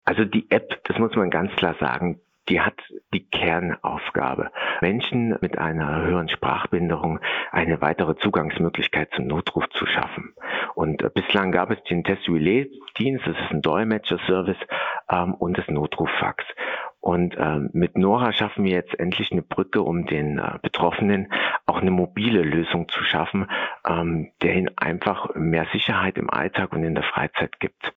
Interview - Nora App - PRIMATON